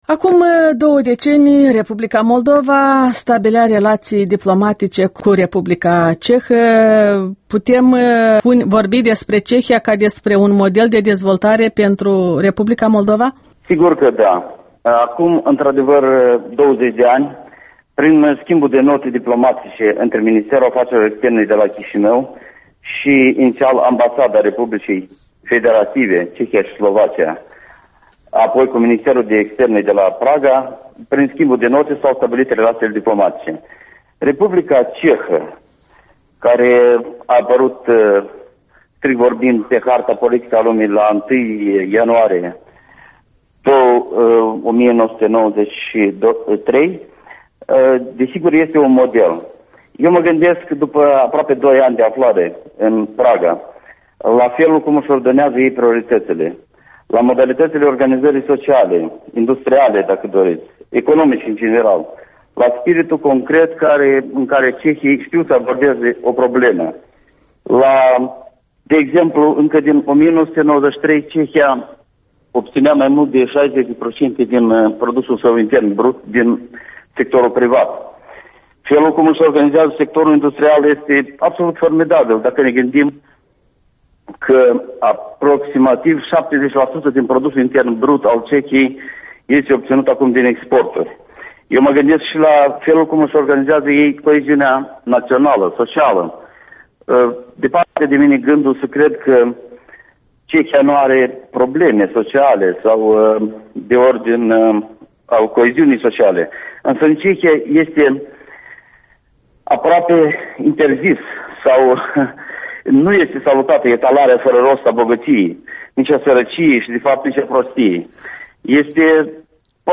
Interviul matinal la EL: cu ambasadorul Ștefan Gorda și relațiile moldo-cehe